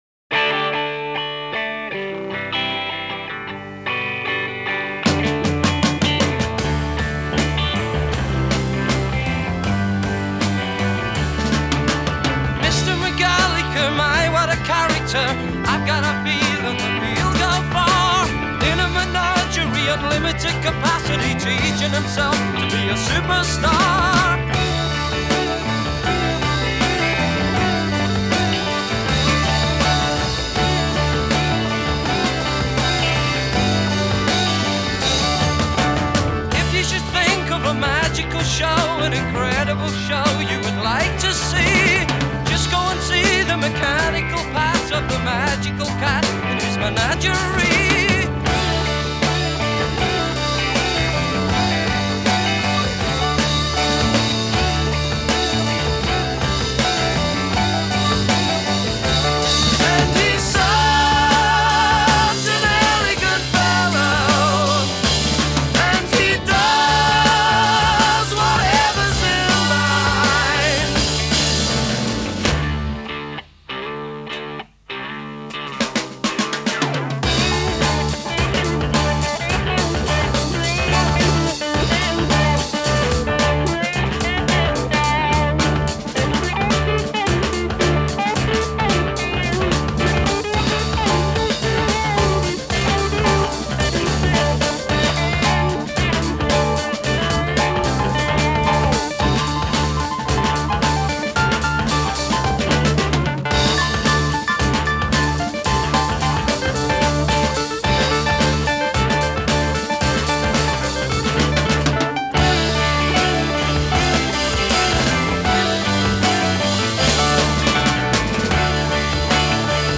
Genre: Classic Rock